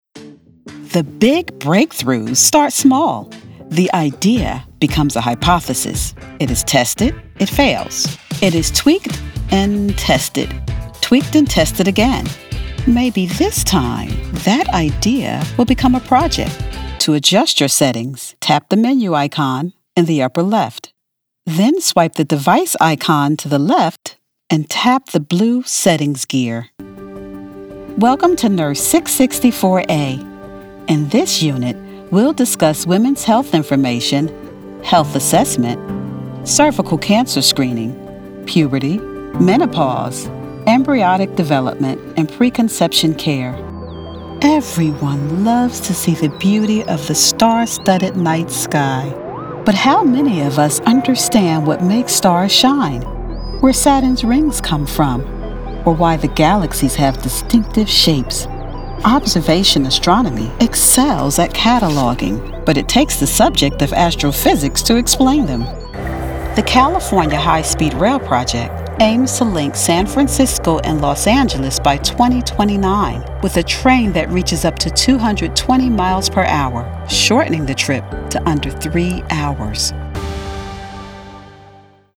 Narration, eLearning
English (North American)